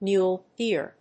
アクセントmúle dèer